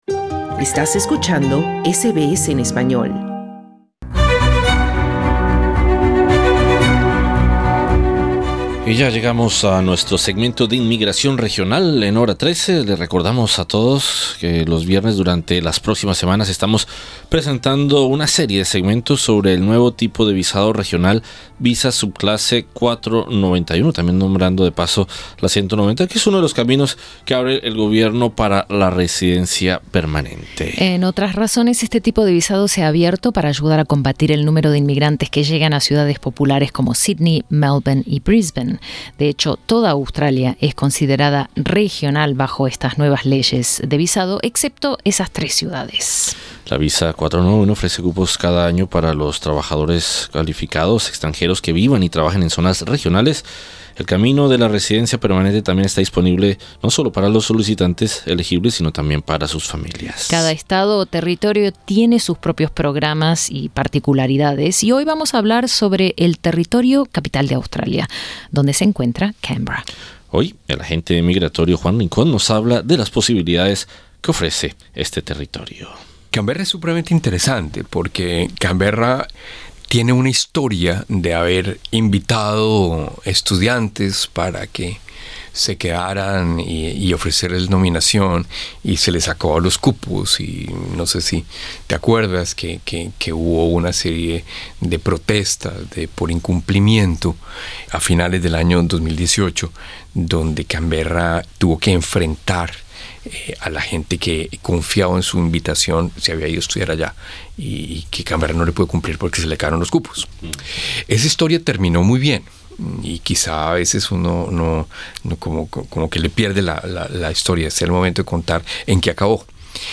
En entrevista con el agente migratorio de Melbourne